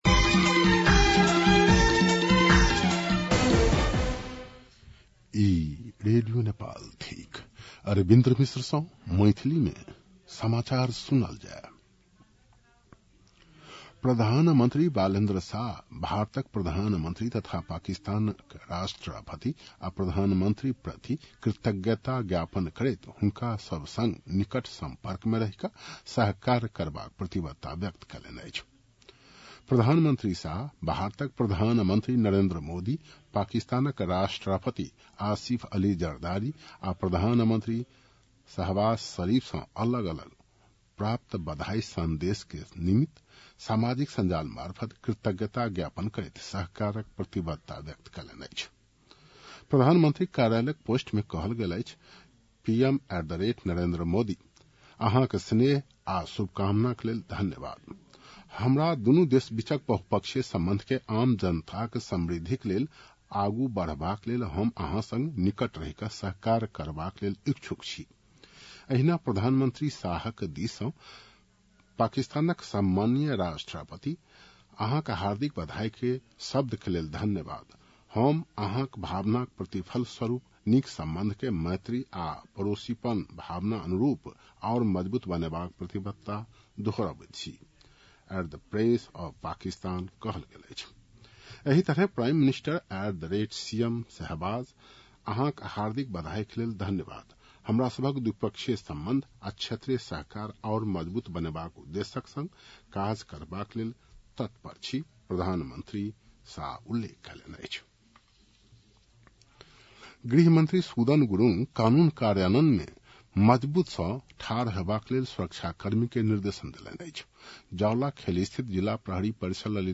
मैथिली भाषामा समाचार : १४ चैत , २०८२